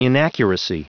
Prononciation du mot inaccuracy en anglais (fichier audio)
Prononciation du mot : inaccuracy